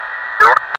Walkie-Talkie Beep
A short walkie-talkie transmission beep with click and brief static burst
walkie-talkie-beep.mp3